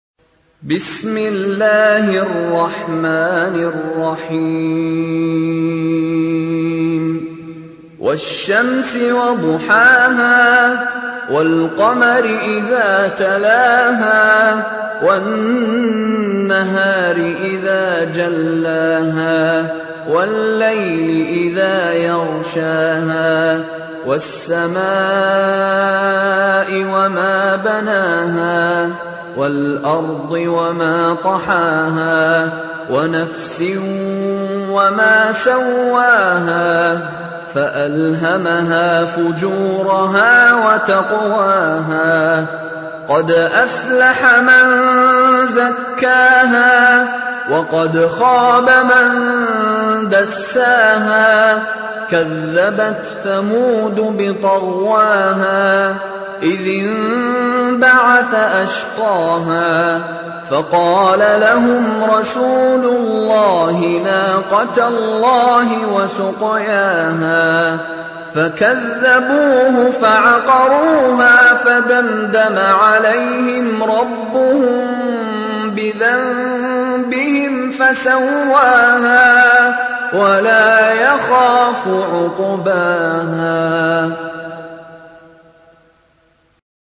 Récitation par Mishary El Afasi